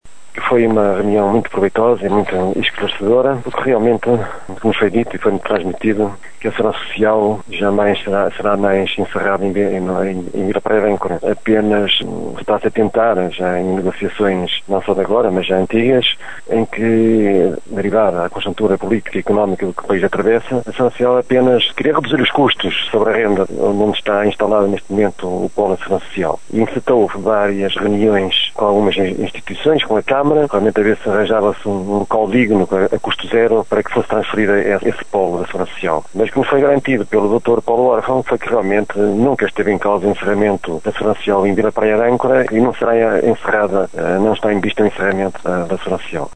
O presidente da Junta de Vila Praia de Âncora, Carlos Castro, faz o resumo deste encontro, que considera ter resultado em boas notícias já que a alternativa foi encontrada.